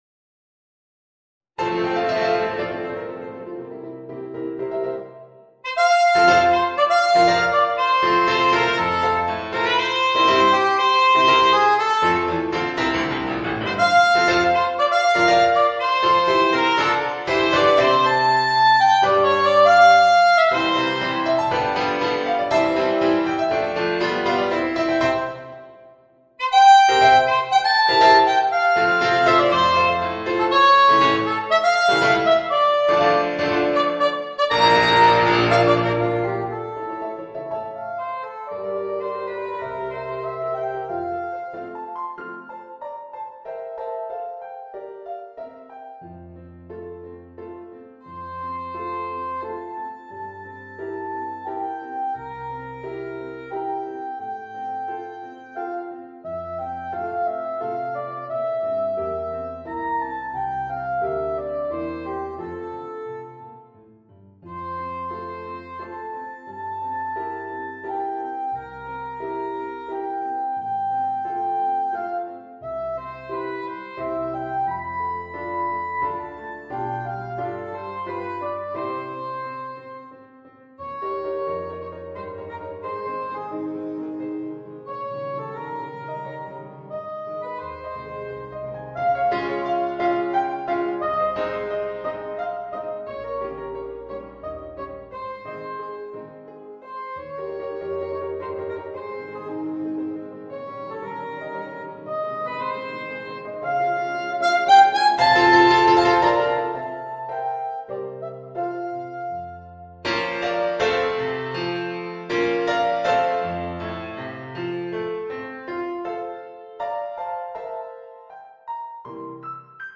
Voicing: Soprano Saxophone and Piano